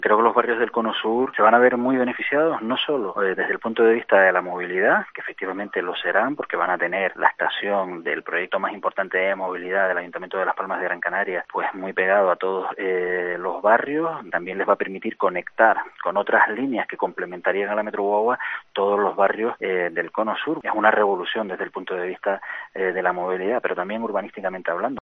Jose Eduardo Ramírez, concejal de movilidad del ayuntamiento de Las Palmas de Gran Canaria